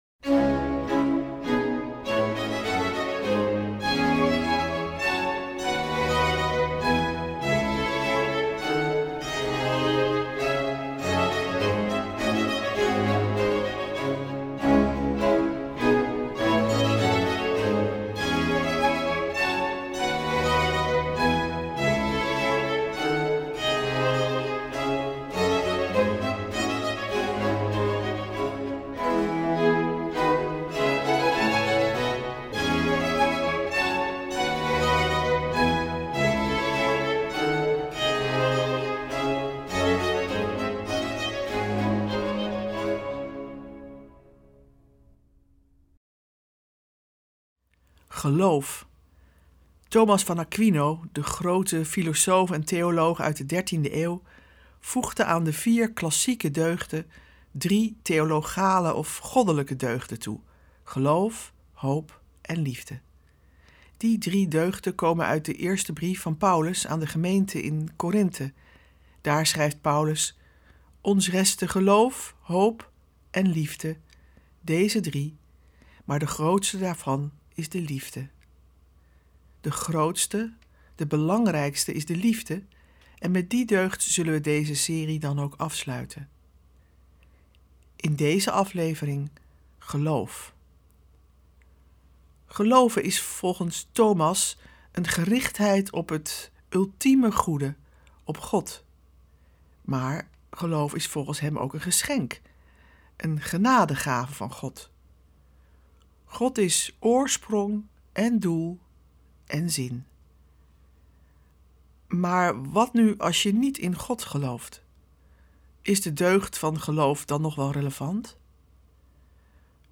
Meditaties Op Weg Naar Pasen 2023